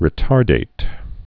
(rĭ-tärdāt, -dĭt)